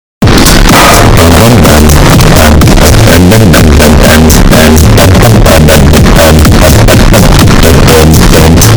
melstroi super bass Meme Sound Effect